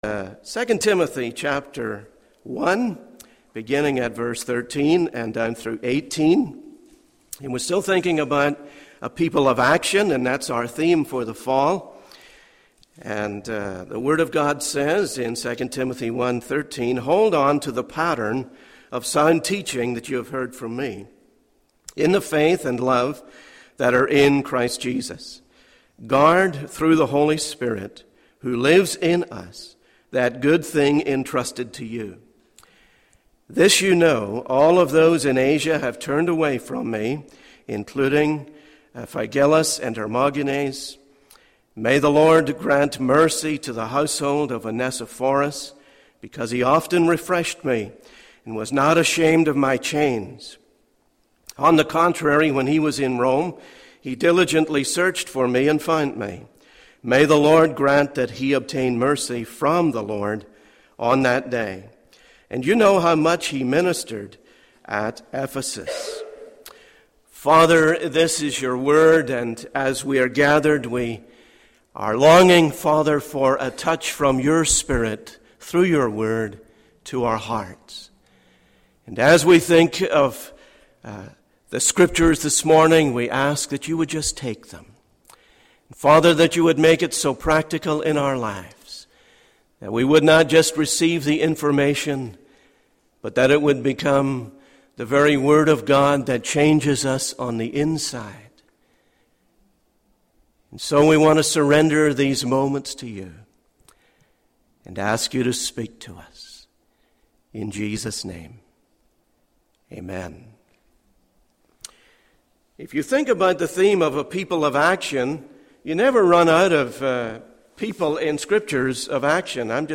In this sermon, the speaker shares personal experiences and anecdotes to emphasize the importance of encouragement in the lives of believers.